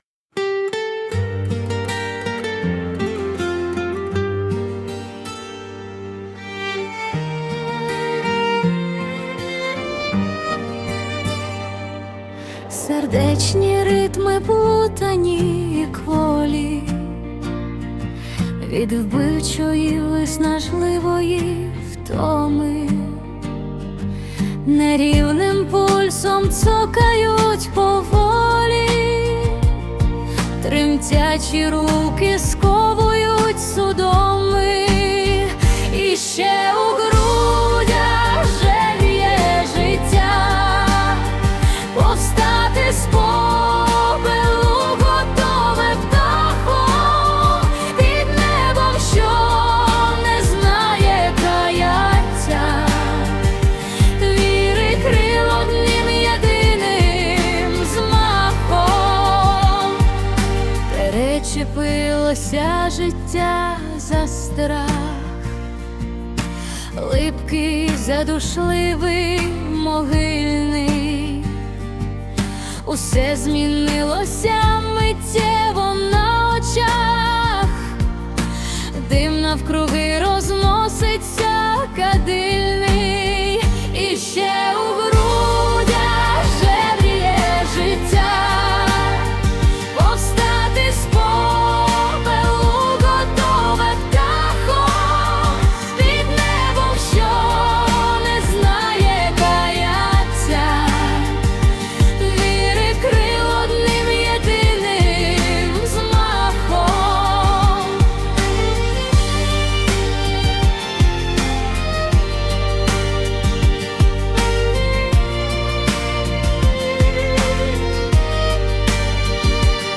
Текст авторський...музика і виконання - ШІ